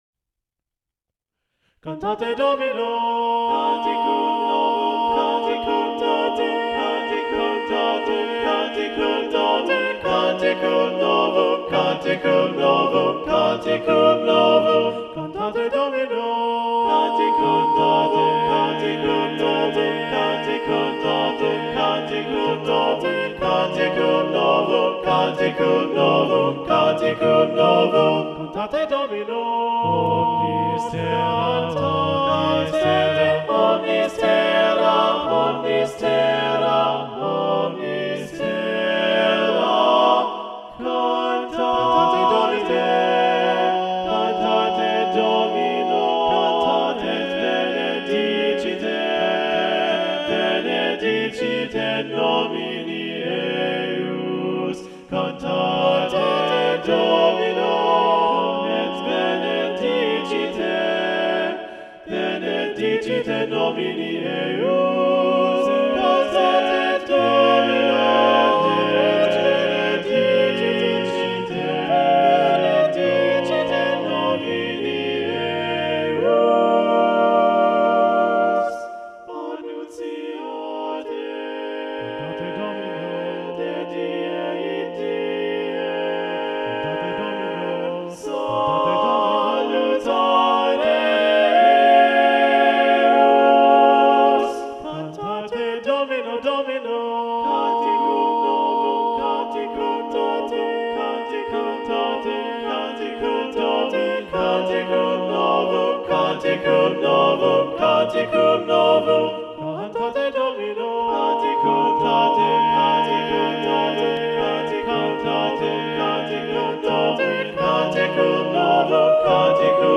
Accompaniment:      A Cappella
Music Category:      Choral